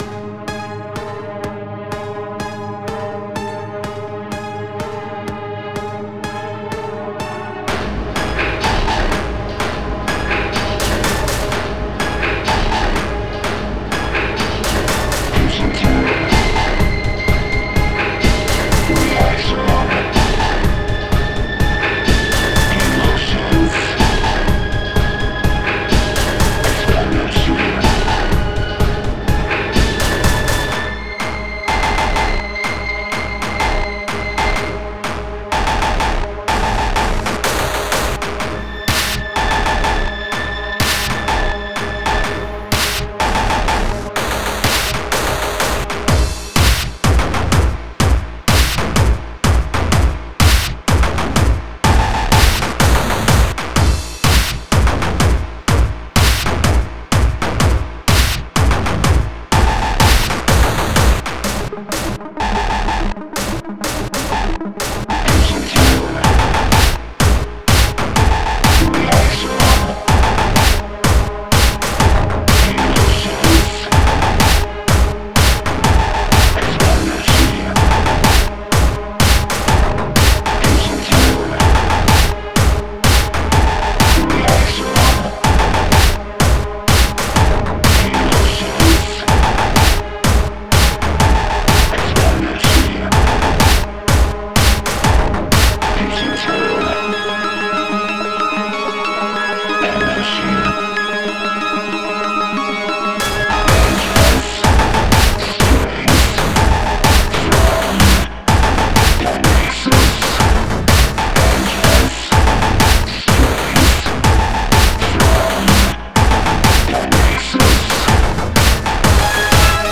EBM, Industrial, Dark Electro, Cyberindustrial, dark techno